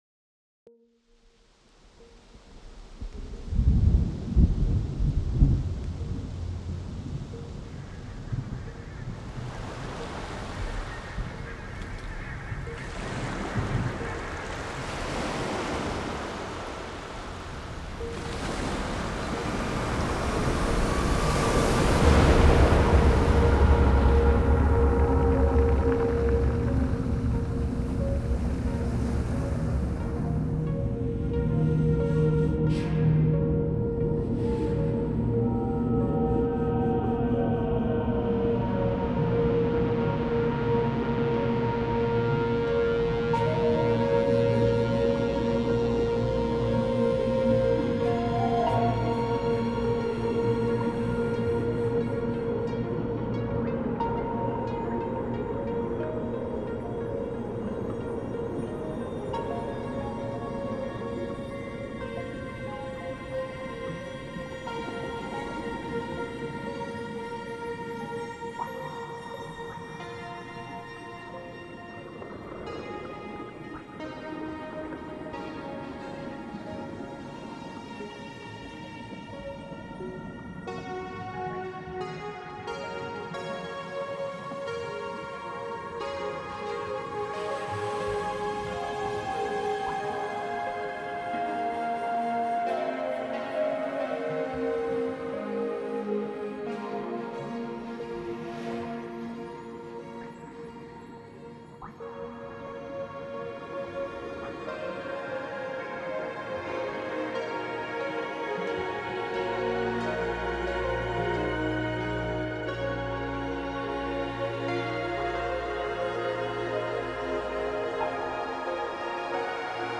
Orchester und EDM in einer Story über Atlantis
Der Mix an Stilrichtungen ist noch wilder als zuvor.
Dieser Track hat nichts von einem Song mit klassischer Strophe/Refrain-Einteilung. Mit den ambientartigen Build Ups, die Synths und Orchester vereinen, bin ich zufrieden. Der erste Chorus mit dem Synth Arp geht in Richtung Melodic Techno + Orchester. Der folgende Break geht noch mehr auf EDM/Techno.
Die Kick geht im hinteren Chorus ziemlich unter.